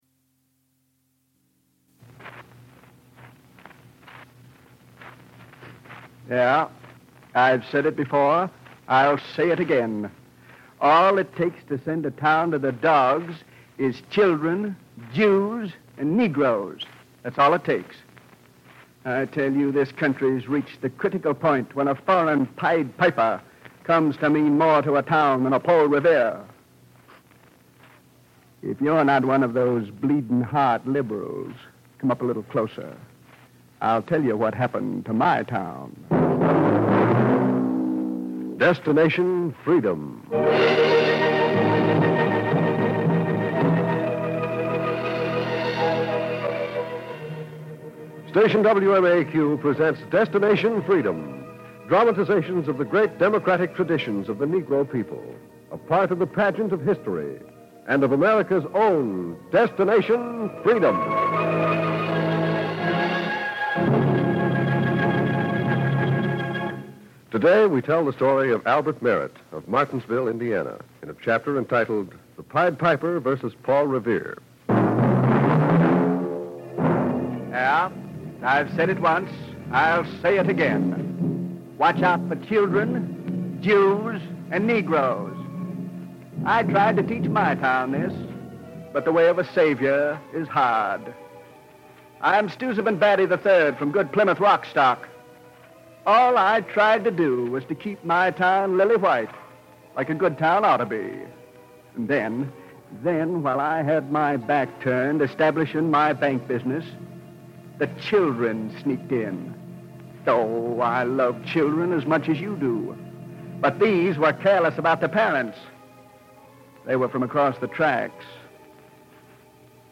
"Destination Freedom" was a pioneering radio series written by Richard Durham that aired from 1948 to 1950. The series aimed to highlight the achievements and struggles of African Americans, often focusing on historical figures and events that were underrepresented in mainstream media.